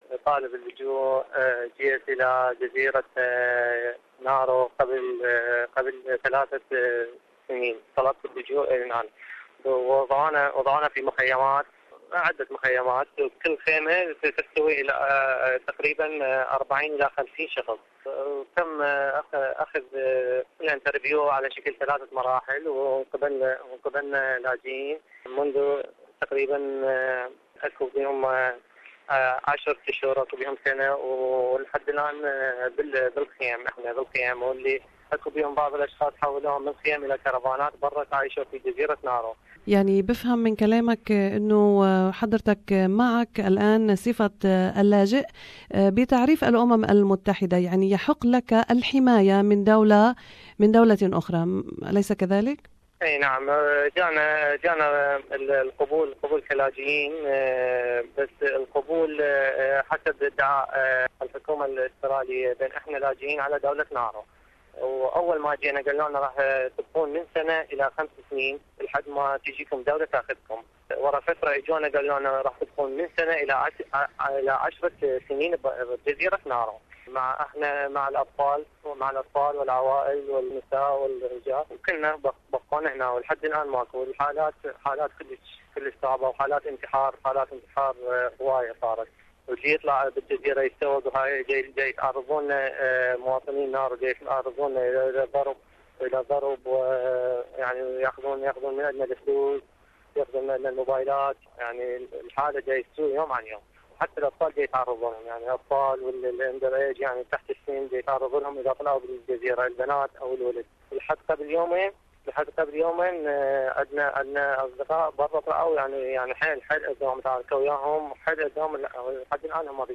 لاجئ في معتقل جزيرة نارو يتحدث بعد رفض أستراليا لما جاء في تحقيق برلماني حول حصول سوء معاملة في المعتقل